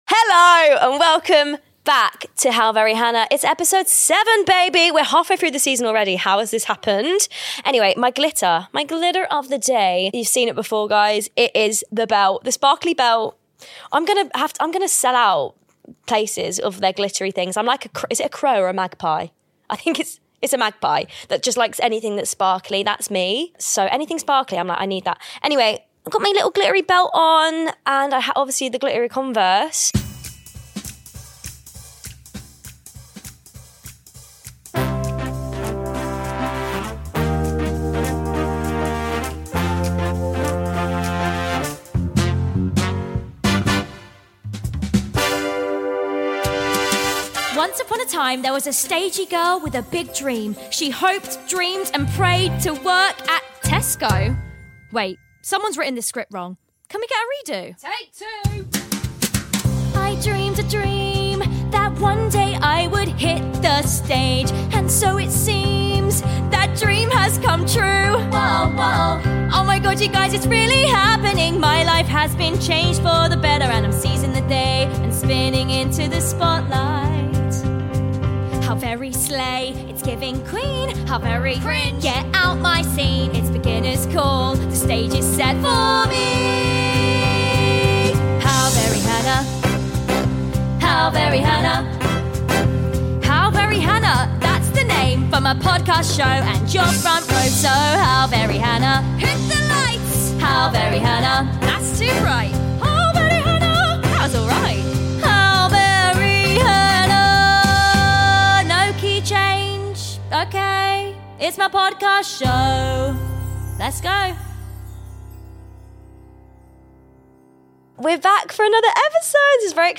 Yep... it’s episode seven of my stagey podcast and I’m out in the wild roping in unsuspecting members of the public for some very stagey challenges, karaoke included.